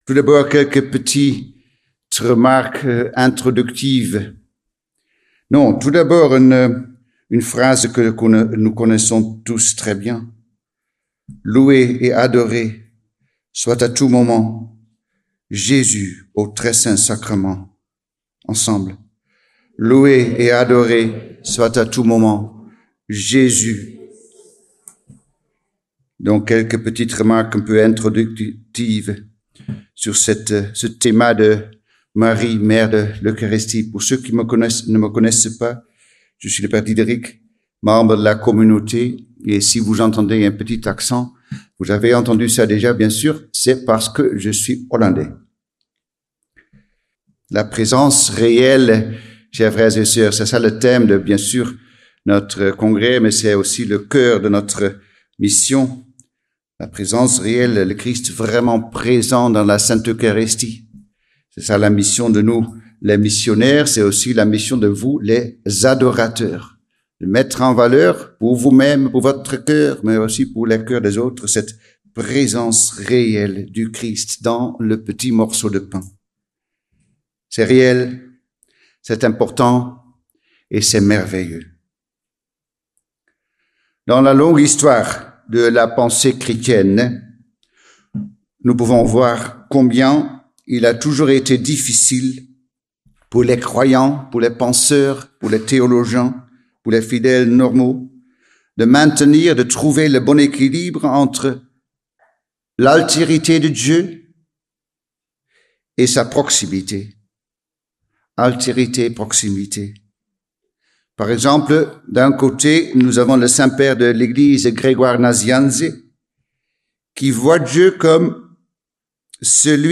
Toulon - Adoratio 2024